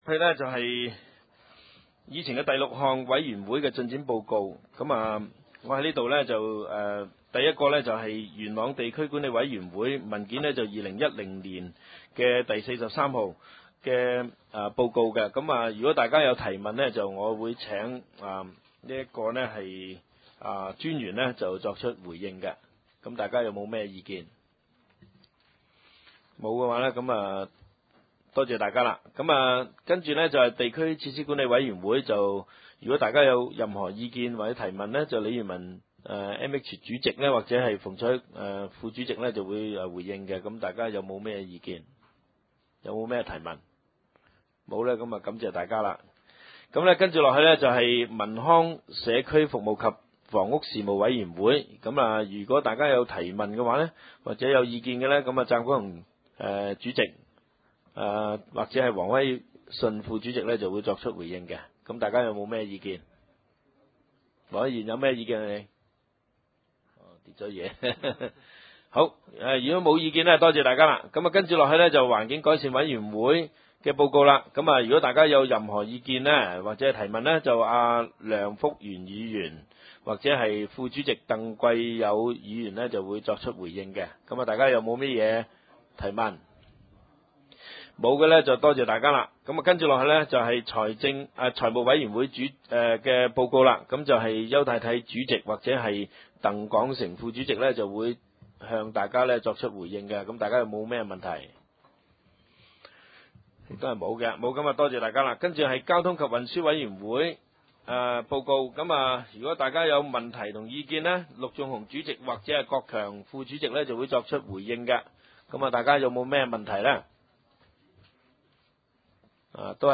點：元朗橋樂坊二號元朗政府合署十三樓元朗區議會會議廳